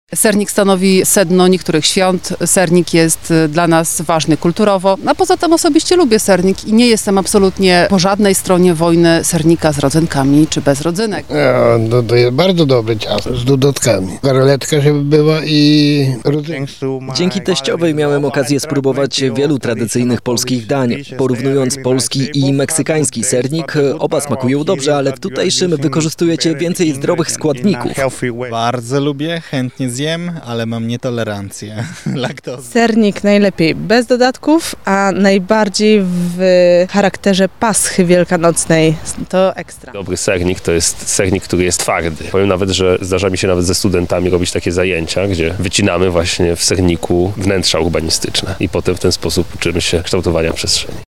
W jakiej formie jedzą go lublinianie? O to zapytaliśmy mieszkańców naszego miasta:
mieszkańcy